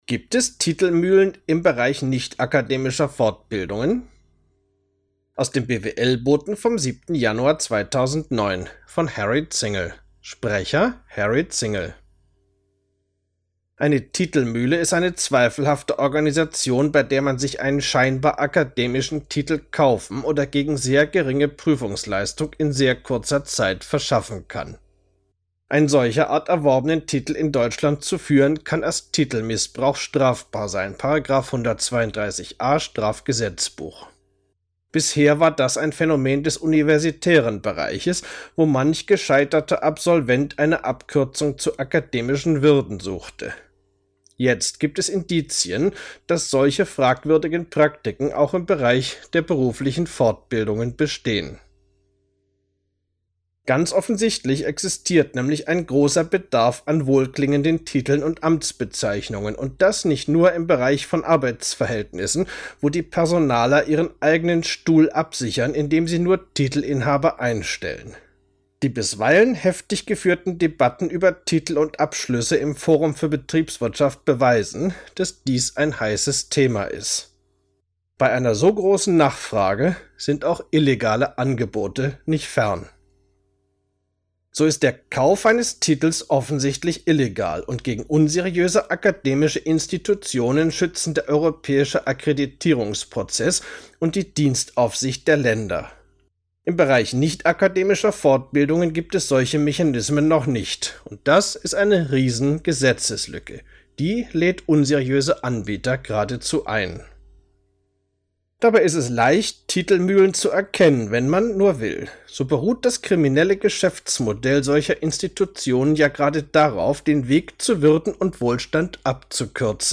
Artikel als MP3 anhören, gesprochen vom Autor: